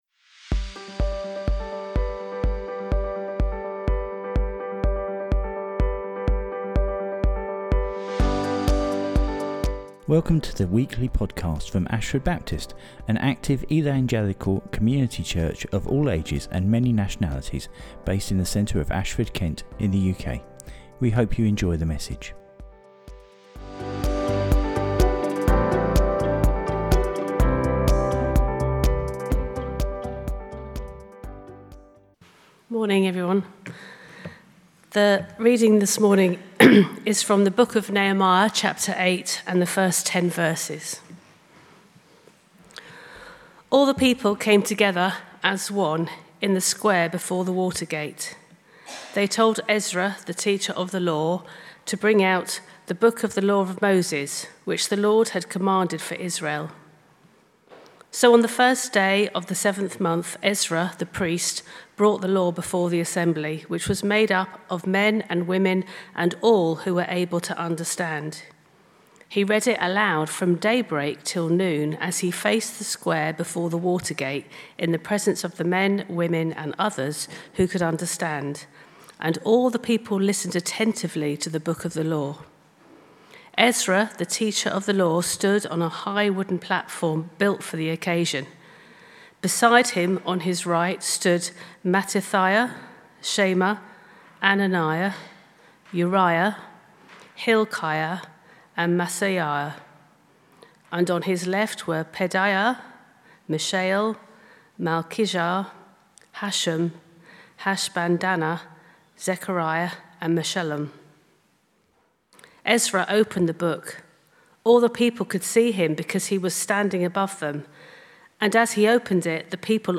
The bible readings are from Nehemiah 8.